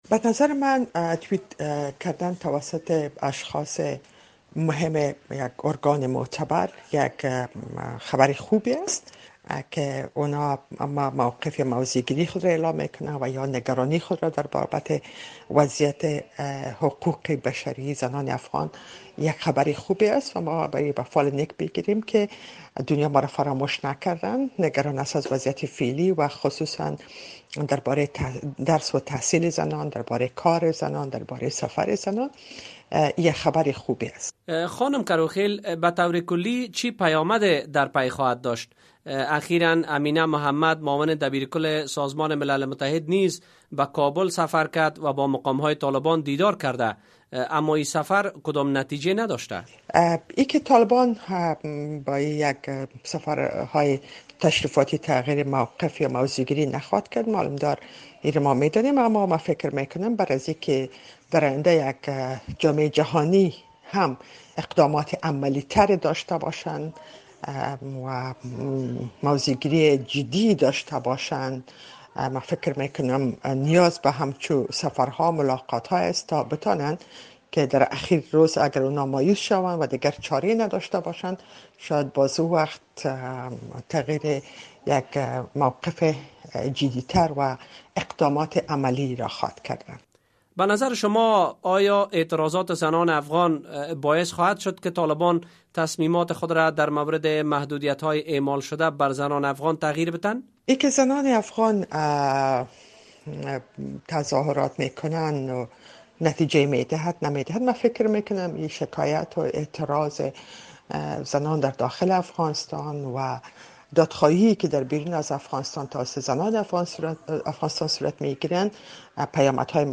شیکنی کروخیل، فعال حقوق زنان و یکی از اعضای ولسی جرگه در نظام جمهوری مخلوع افغانستان می‌گوید که توییت‌ها، اعلامیه ها و نوشته های سازمان‌ها، نهادها و شخصیت‌های بلند پایه بخاطر حقوق زنان افغانستان نشان می‌دهد که جهان از زنان افغانستان حمایت می‌کند.